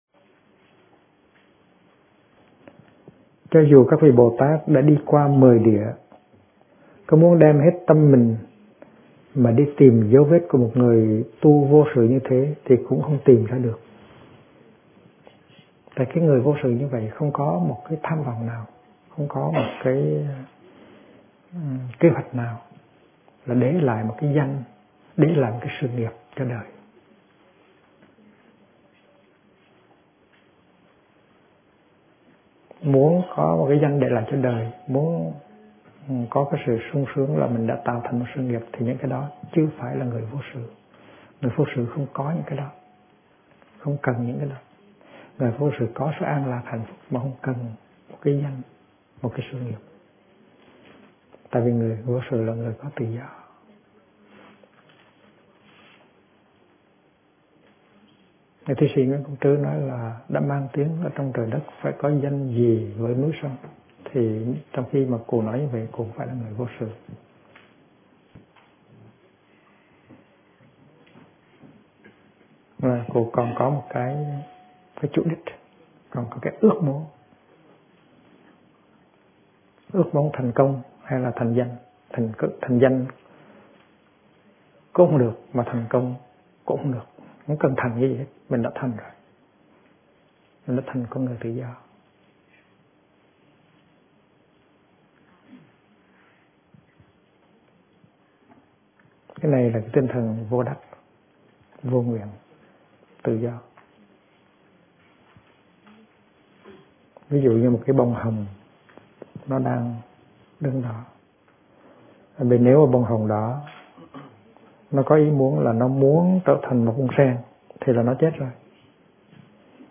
Kinh Giảng Tâm Và Cảnh Tướng Tục - Thích Nhất Hạnh